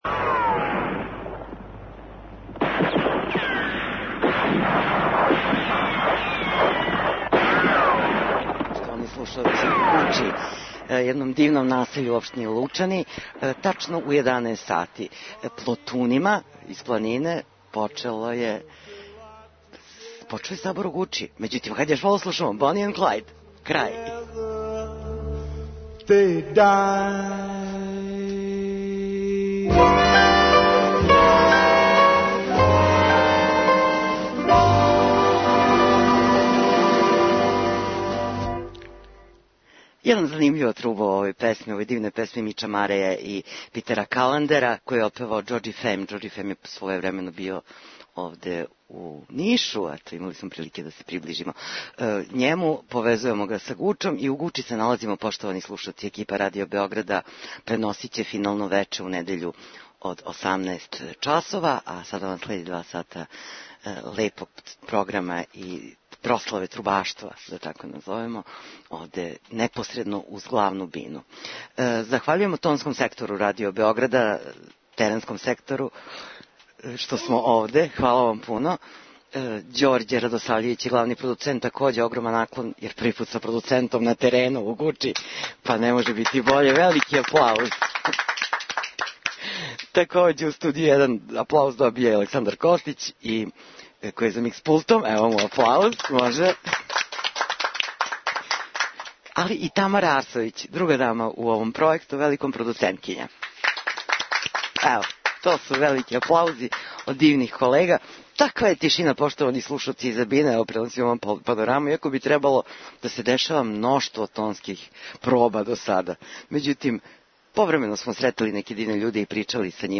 Специјална двосатна емисија Поп карусел емитује се са 61. Драгачевског сабора трубача.